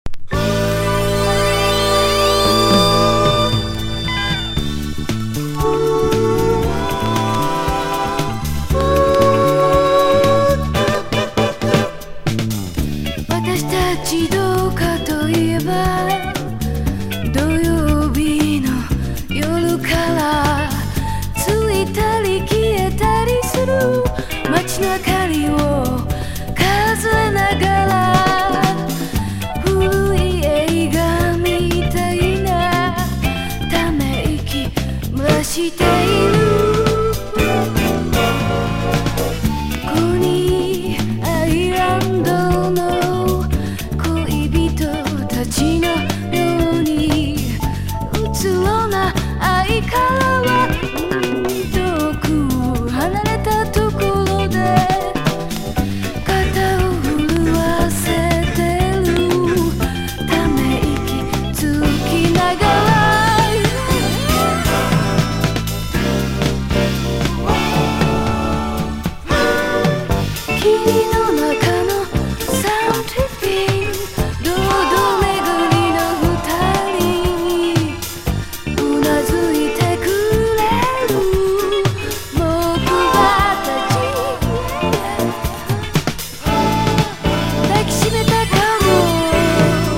アンニュイなボーカルが素晴らしい!!
CITY POP / AOR